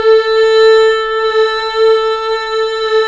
Index of /90_sSampleCDs/Club-50 - Foundations Roland/VOX_xFemale Ooz/VOX_xFm Ooz 1 M